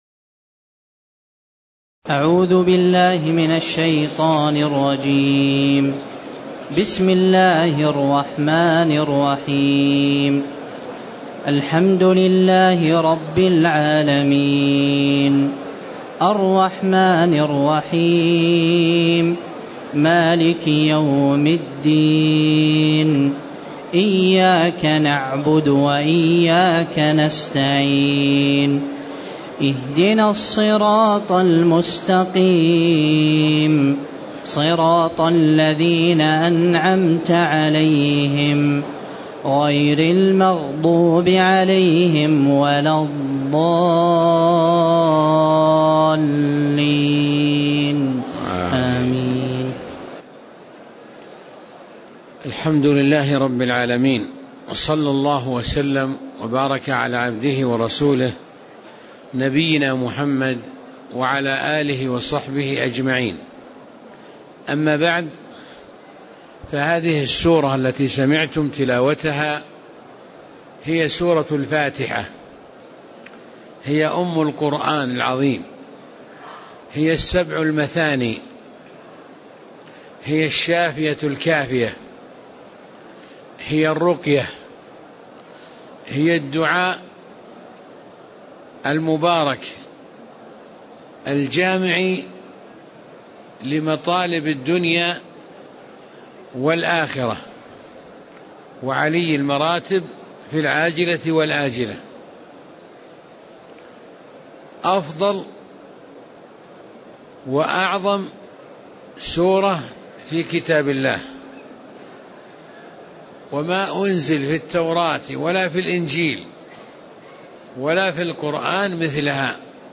تاريخ النشر ٢ رجب ١٤٣٨ هـ المكان: المسجد النبوي الشيخ